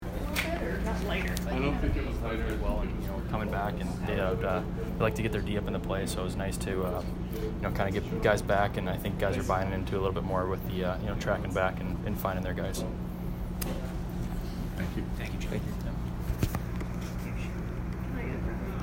Jonny Brodzinski post-game 2/25